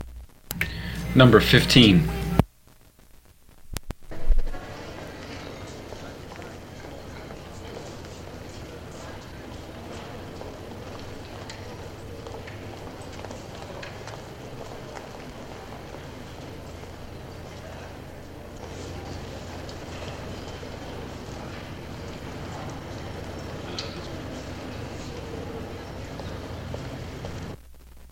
Vintage Ambience » G1601Small Airport Ambience
描述：Small airport or airfield ambience. Prop planes taxi. Multiple engines. These are highquality copies of original nitrate optical Hollywood sound effects from the 1930s 40s, transferred to fulltrack tape in the early 1970s. I have digitized them for preservation, but they have not been restored and have some noise.
声道立体声